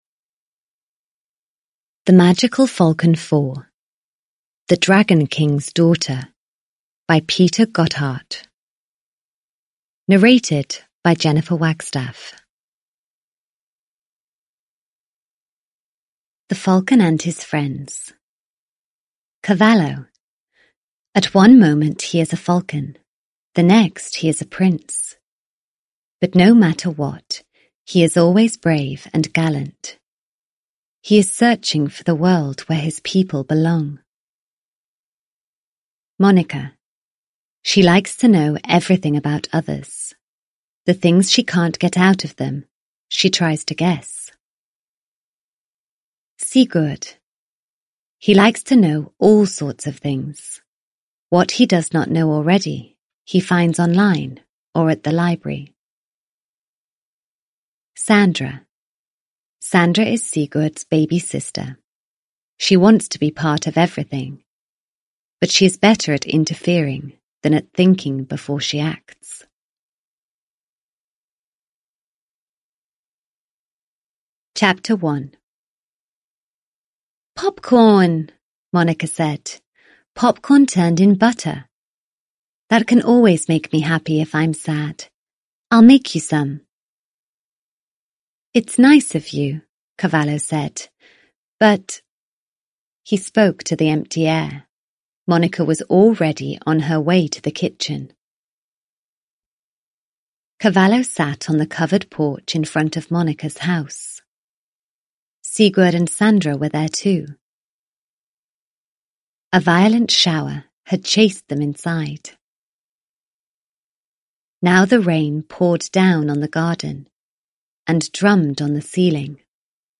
The Magical Falcon 4 - The Dragon King's Daughter (ljudbok) av Peter Gotthardt